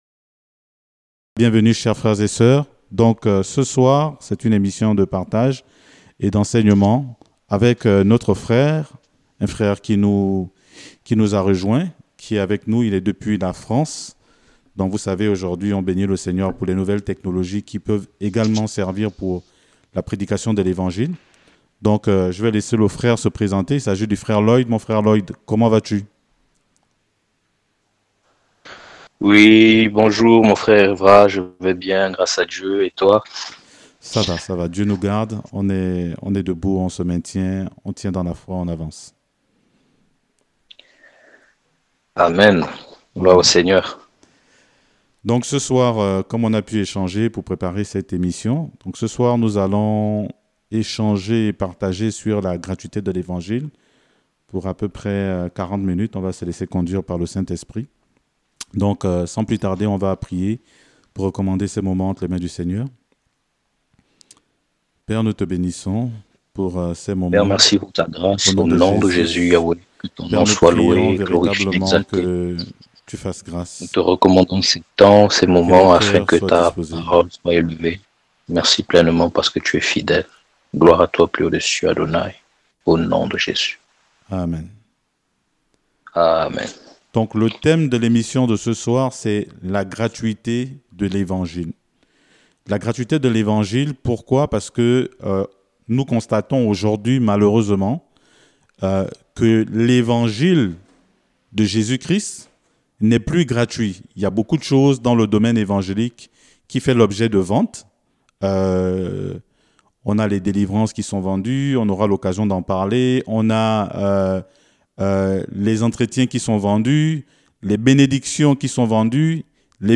Enseignement